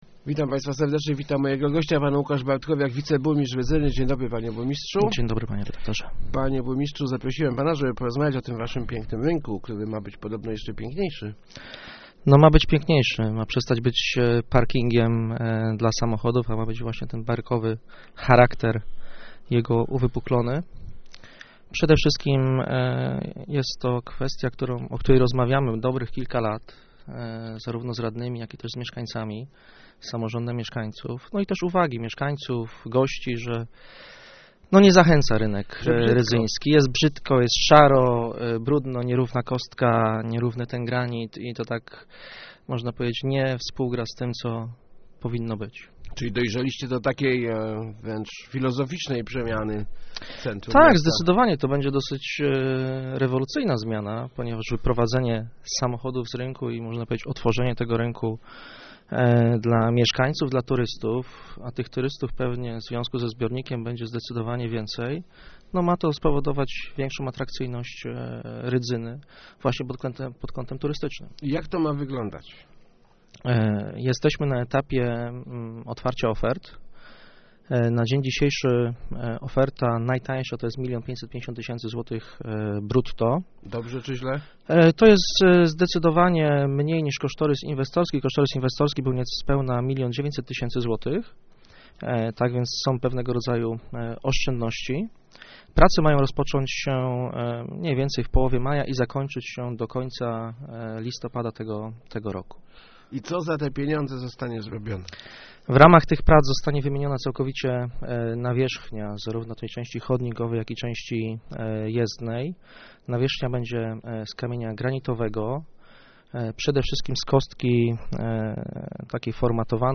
bartkowiak80.jpgNasz Rynek musi stać się atrakcyjny dla turystów, zwłaszcza że wkrótce będzie gotowy nasz zalew - mówił w Rozmowac Elki wiceburmistrz Rydzyny Łukasz Bartkowiak. Jeszcze w tym roku zostanie wymieniona nawierzchnia Rynku, jednak zmiany idą o wiele dalej - znikną z niego samochody, na miejscy których pojawić się mają ogródki wiedeńskie.